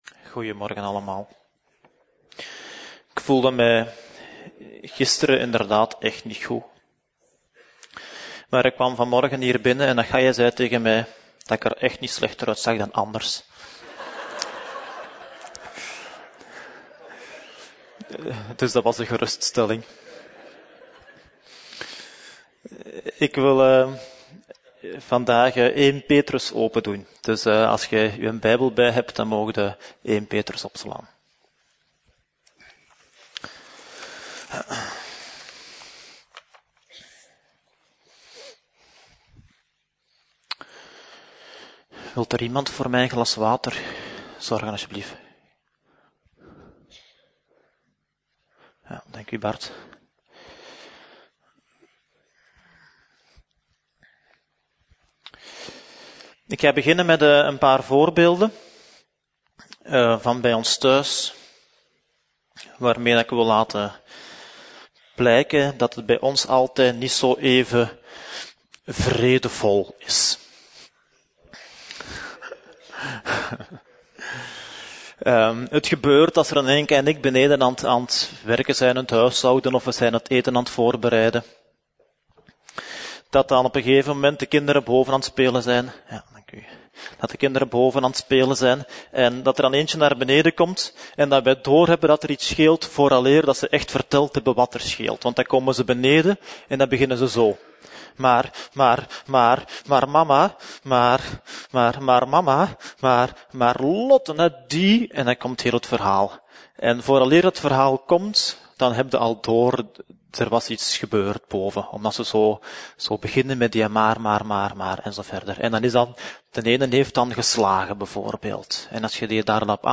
Preek: Onze verantwoordelijkheid - Levende Hoop